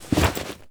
foley_jump_movement_throw_03.wav